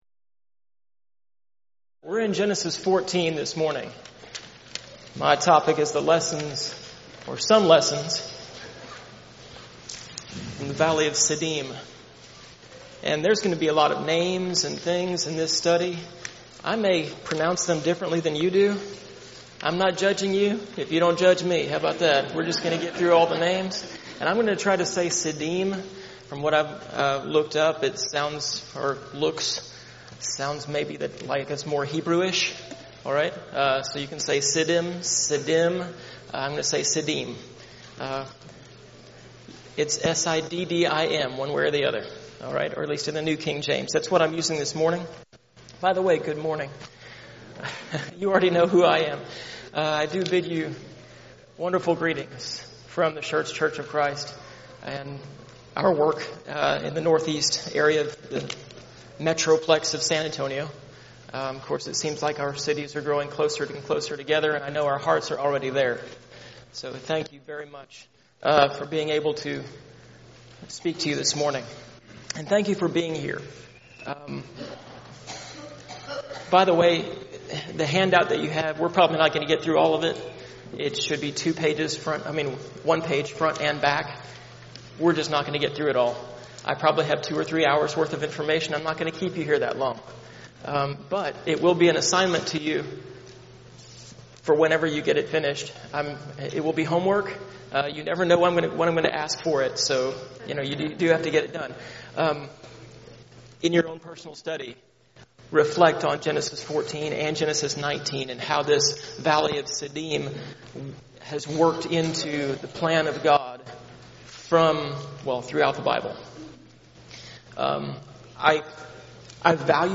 Preacher's Workshop
this lecture